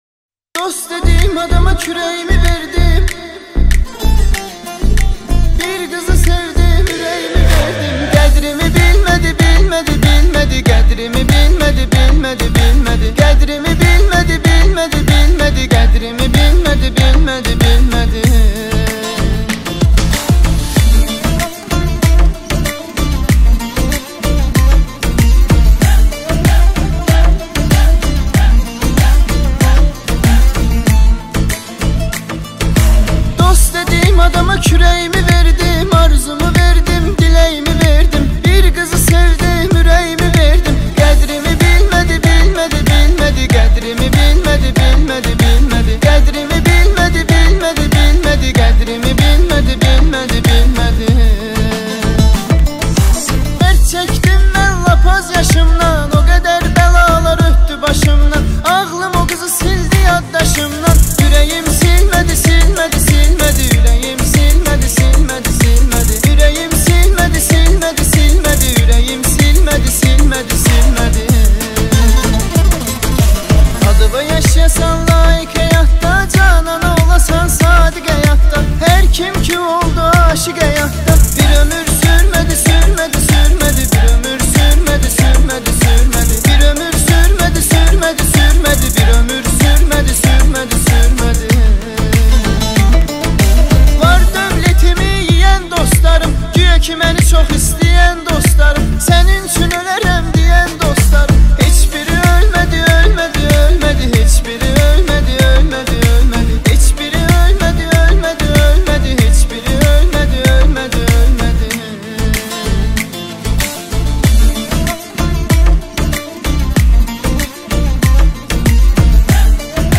با گیتار